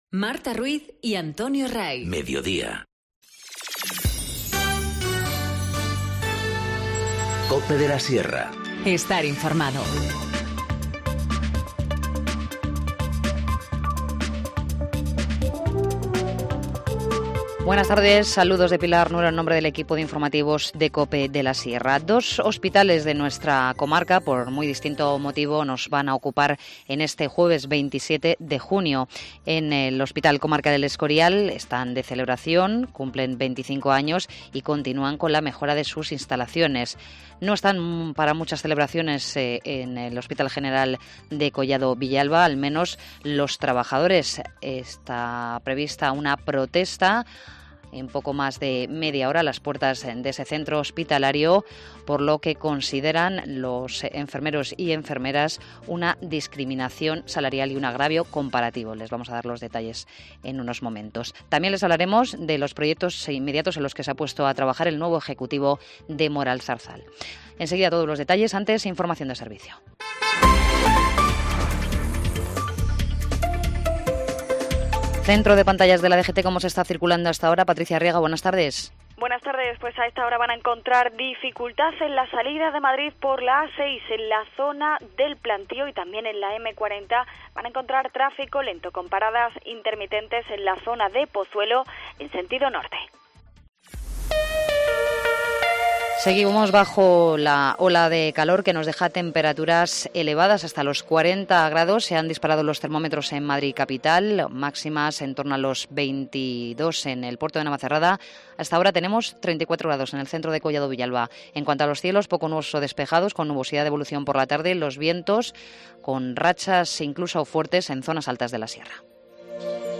Informativo Mediodía 27 junio 14:20h